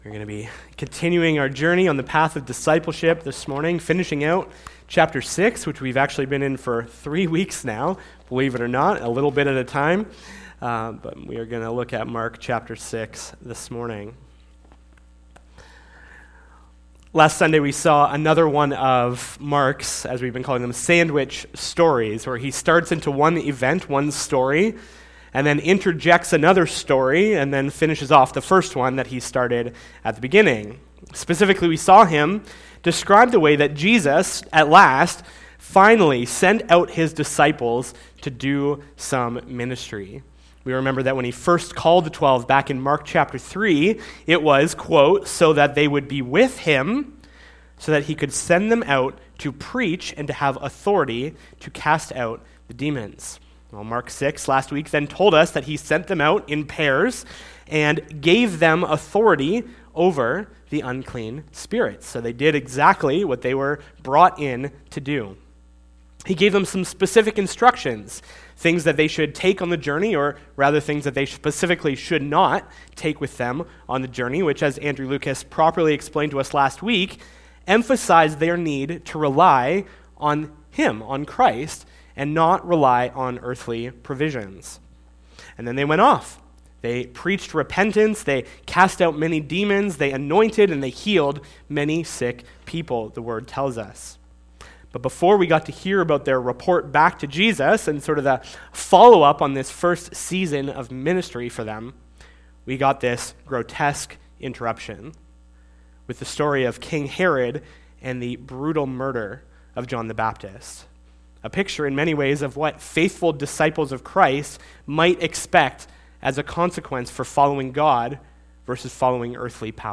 SERMONS - Community Bible Church